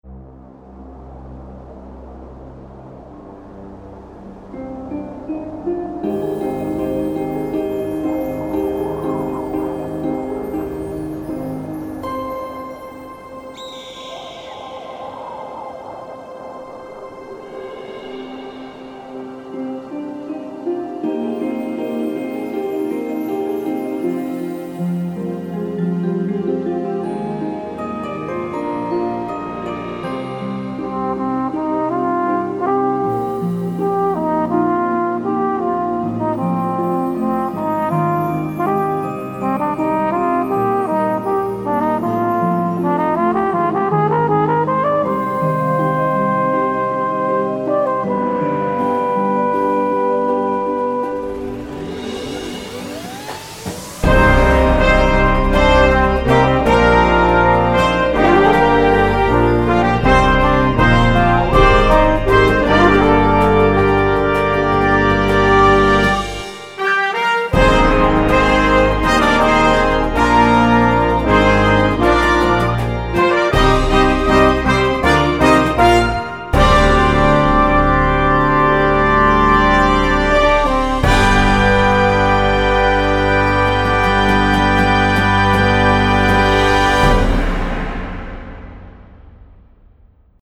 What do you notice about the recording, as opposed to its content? LIVE with optional sound design